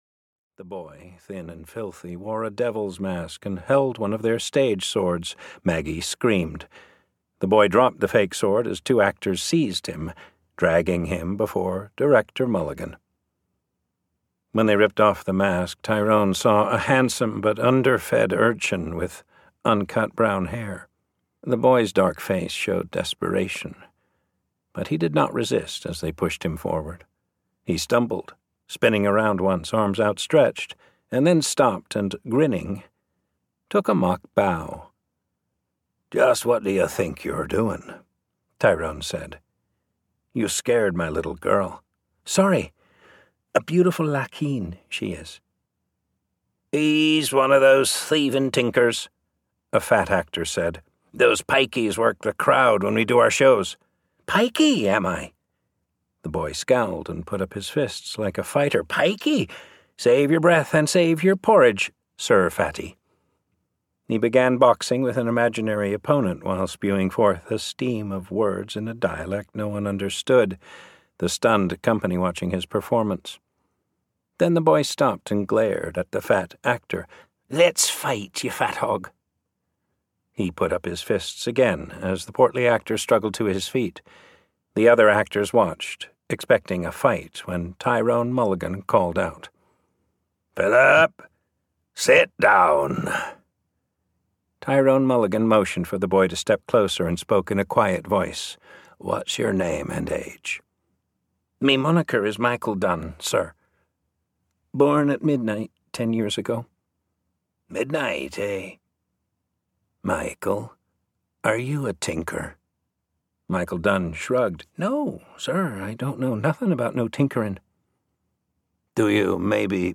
Mulligan (EN) audiokniha
Ukázka z knihy